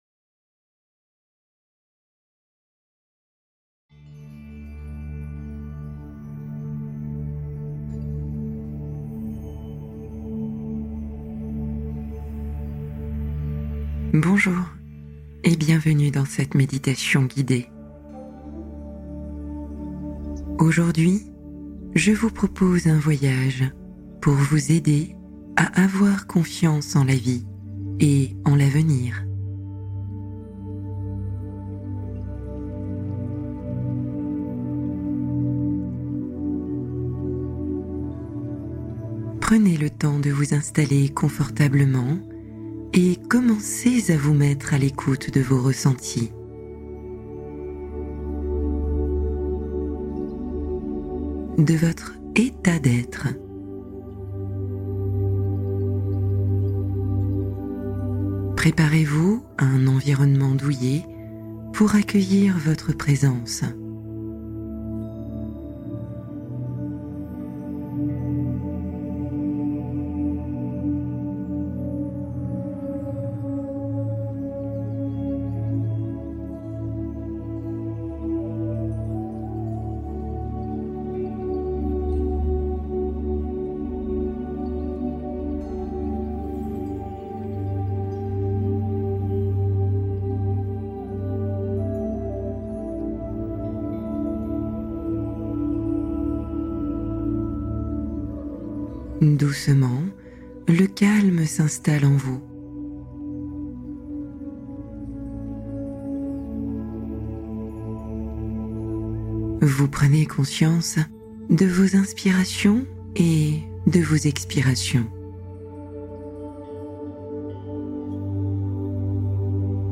Apprenez à faire confiance à la vie | Méditation apaisante pour l’avenir